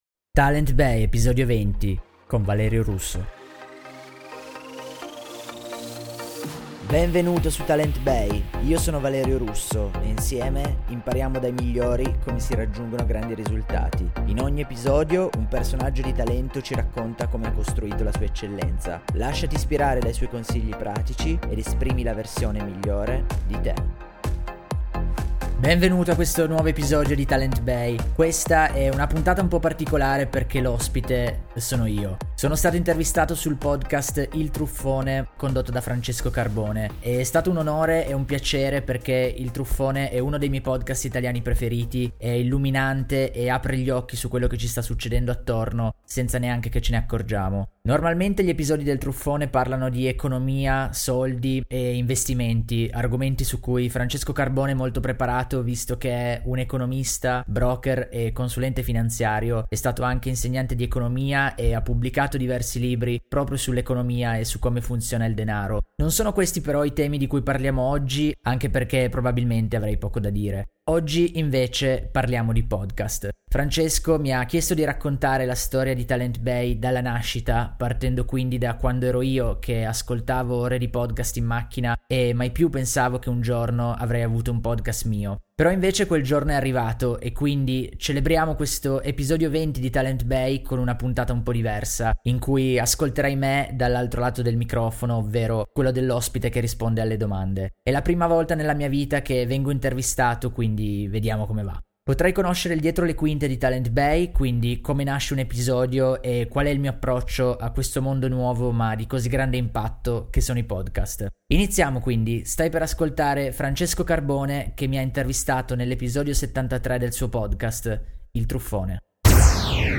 Clicca con il tasto destro per scaricare l’audio dell’intervista